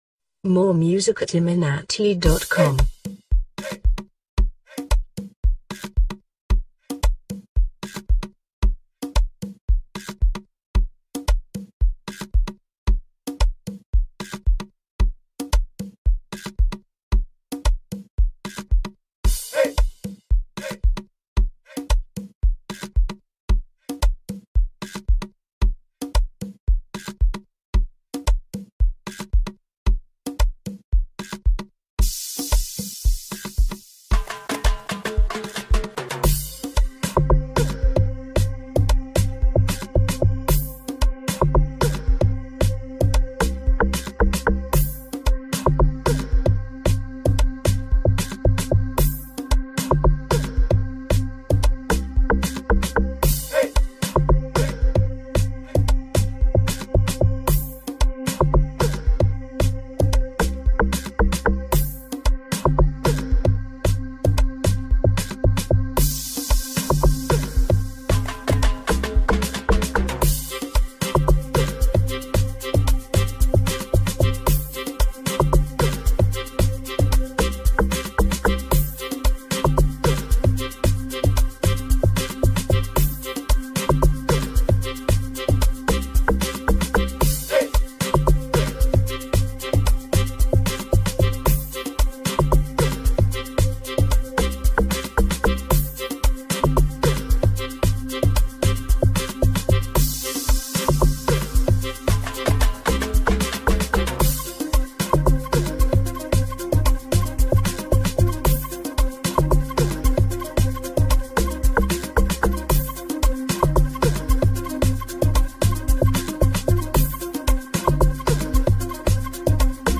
amapiano mixtape